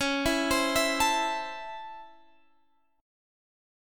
C#mM7#5 chord